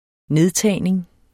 Udtale [ -ˌtæˀjneŋ ]